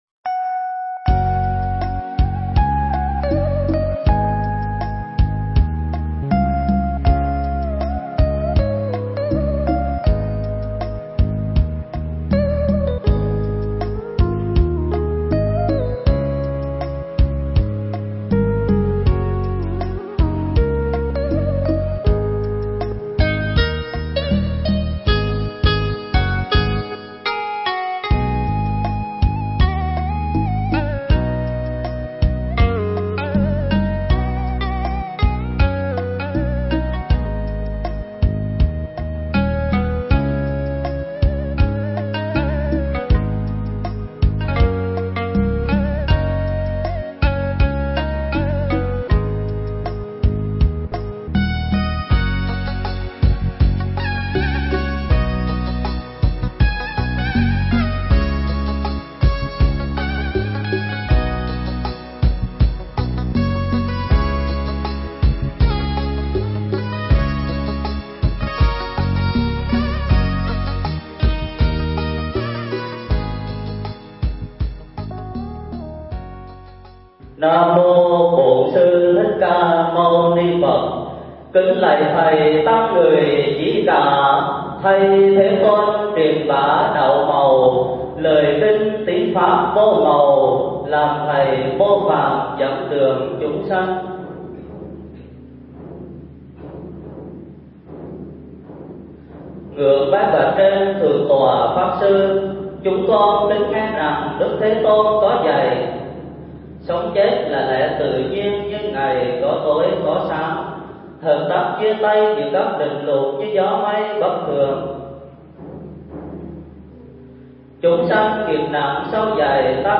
Mp3 Pháp Thoại Bồ Tát Quán Thế Âm – Tín Ngưỡng Và Triết Lý
giảng tại chùa Bửu Đà (quận 10, HCM)